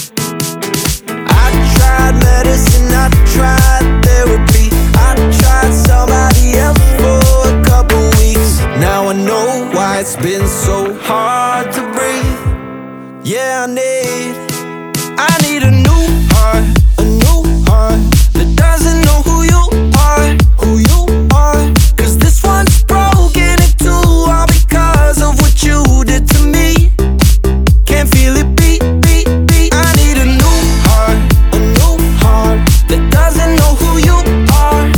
Pop Rock
Жанр: Поп музыка / Рок